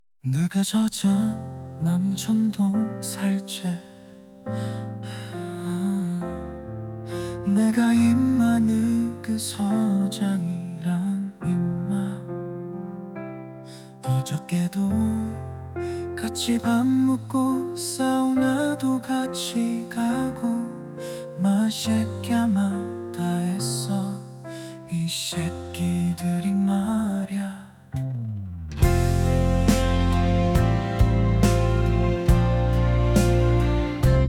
남자 Kpop 아이돌 버전 <느그 서장 남천동 살제 2>